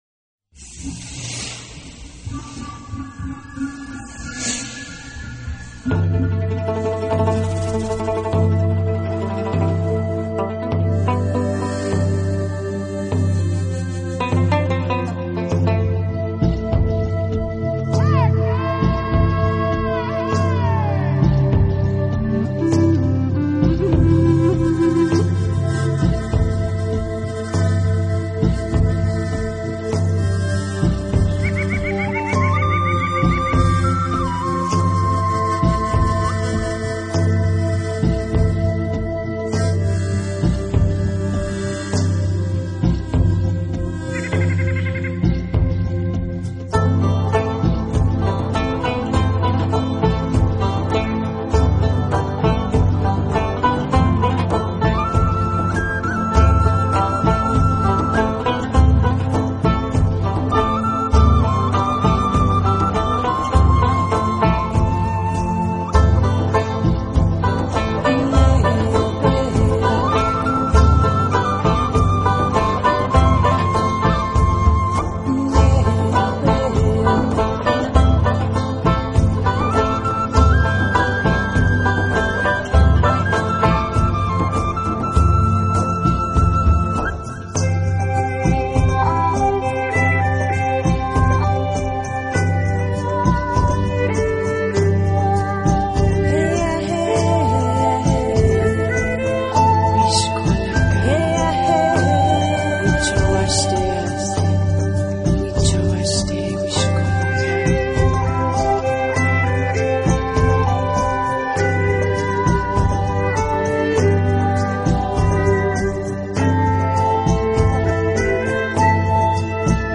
专辑语言：纯音乐
感受到他的风格，激荡而洋溢。